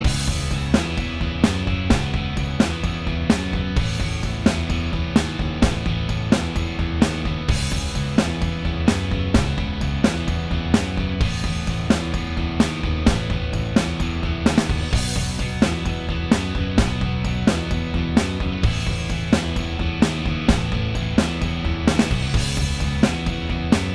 Minus All Guitars Rock 4:20 Buy £1.50